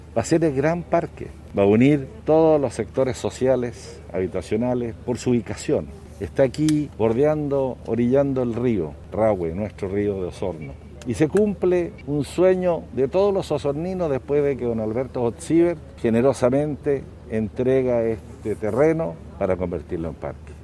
Por su parte, el Intendente de la región de Los Lagos, Harry Jürgensen comentó, este “parque va a unir todos los sectores sociales y habitacionales”.